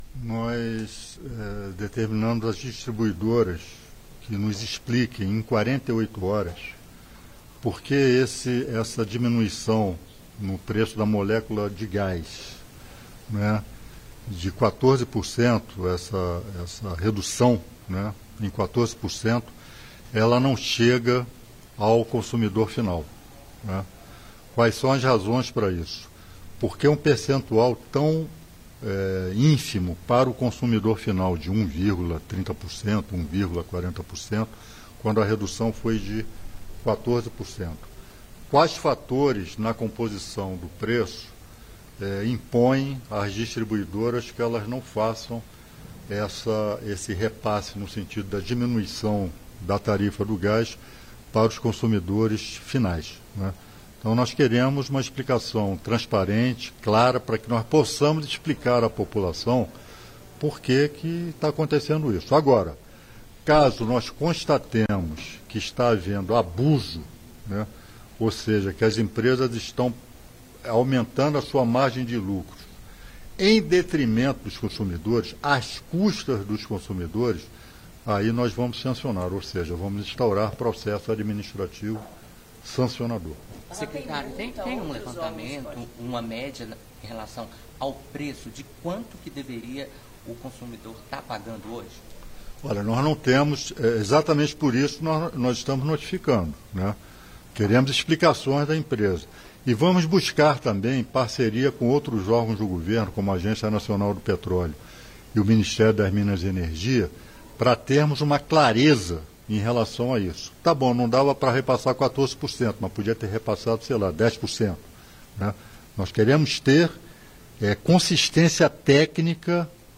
Íntegra da declaração do Secretário Nacional do Consumidor Wadih Damous à imprensa, nesta quarta-feira (30), em Brasília.